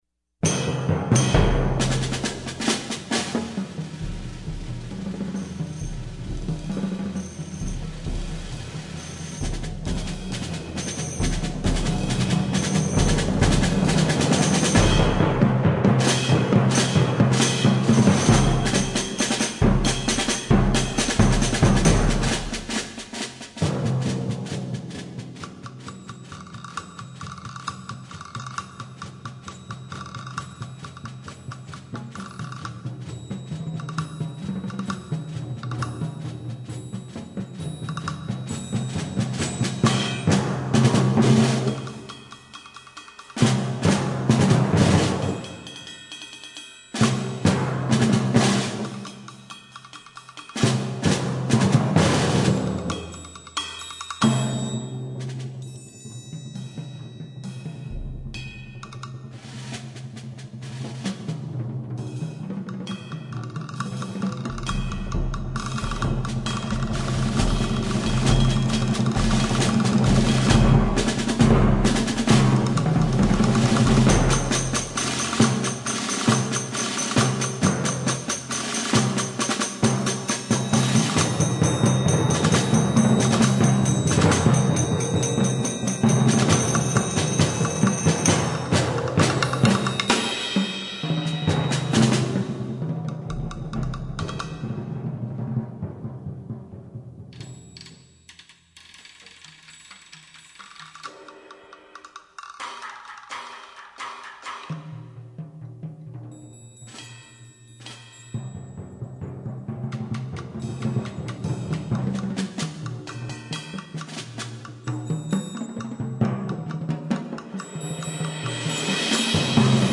Voicing: Percussion Sextet